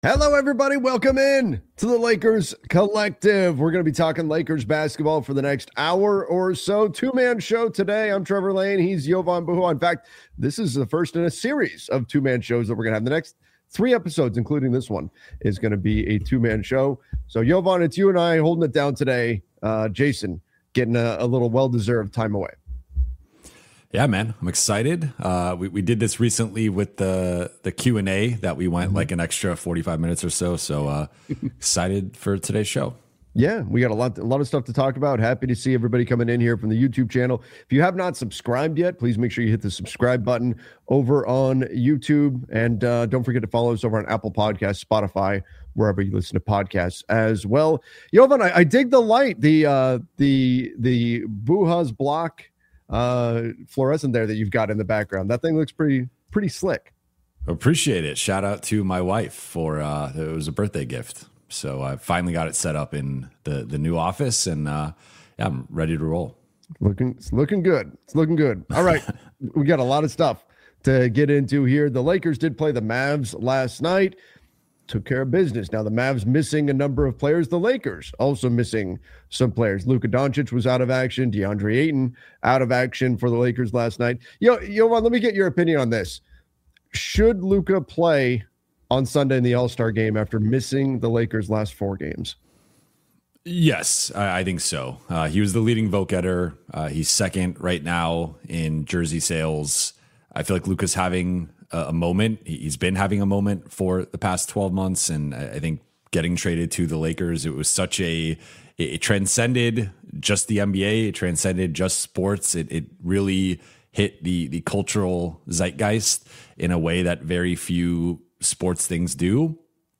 a weekly Los Angeles Lakers roundtable
We go live every Thursday at 10:00 AM PT with sharp analysis, smart X’s-and-O’s talk, and honest conversations about all things Lakers — from game breakdowns and player development to cap moves and playoff paths.